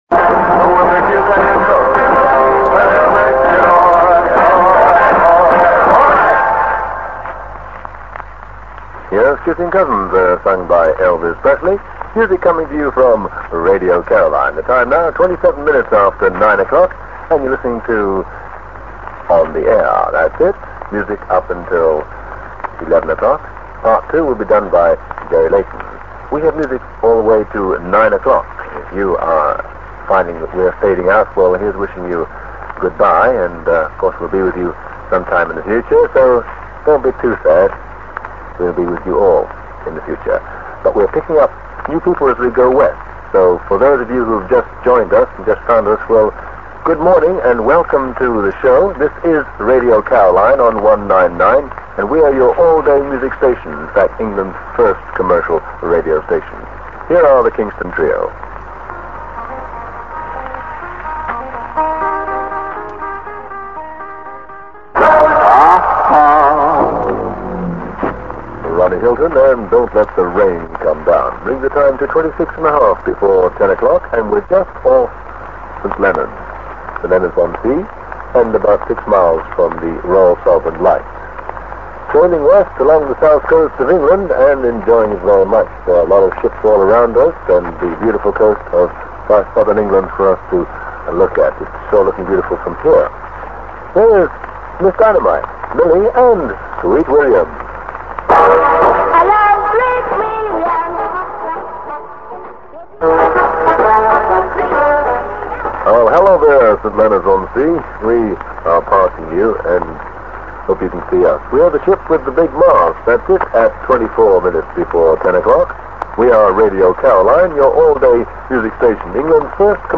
At 12.30am on Saturday 4th July the engine was started, the anchor was raised and the Fredericia began her historic journey.
welcoming new listeners as the ship sails along the south coast, passing St.Leonards